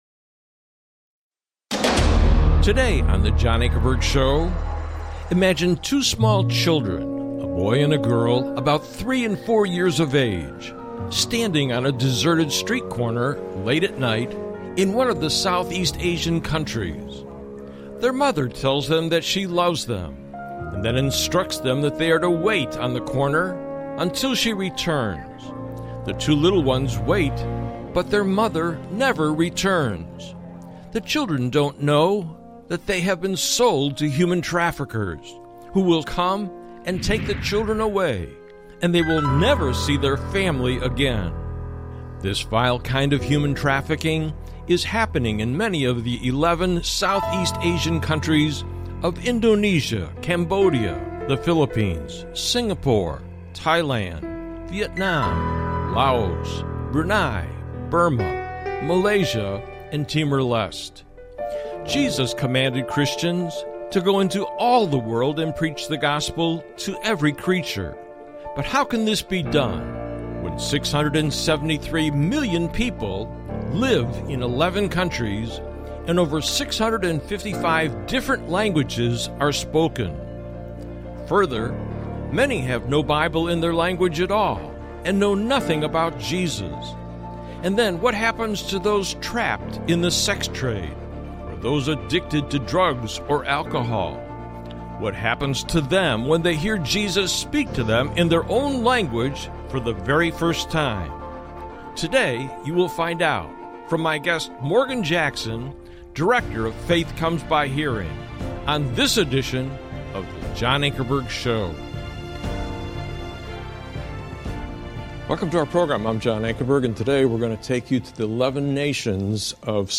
Talk Show Episode, Audio Podcast, The John Ankerberg Show and Set Free in South Asia Until the Whole World Hears - Ep 3 on , show guests , about Set Free,South Asia,the Whole World Hears, categorized as History,News,Politics & Government,Christianity,Society and Culture,Theory & Conspiracy
The formats of choice for this apologetics ministry are informal debates between representatives of differing belief systems, and documentary-styled presentations on major issues in society to which the historic Christian faith has something of consequence to say.